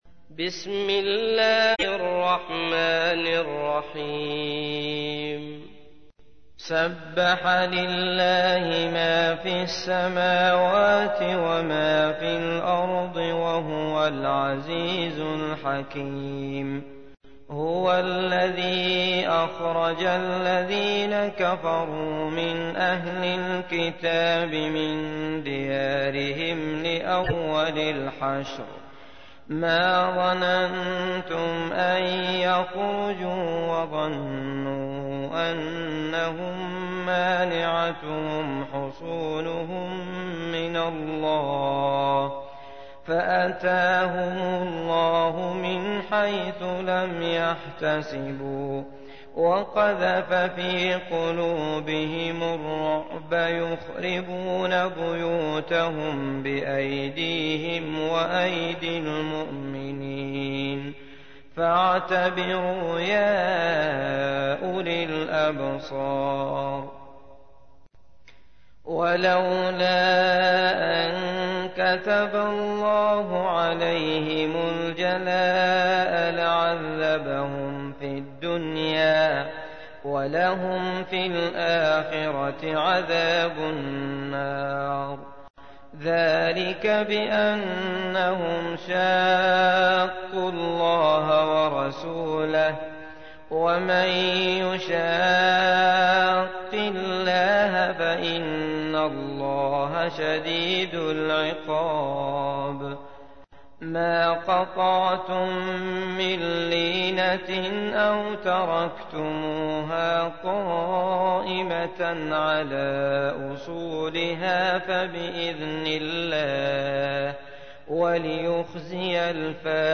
تحميل : 59. سورة الحشر / القارئ عبد الله المطرود / القرآن الكريم / موقع يا حسين